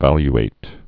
(văly-āt)